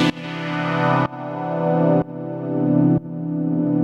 GnS_Pad-dbx1:2_125-C.wav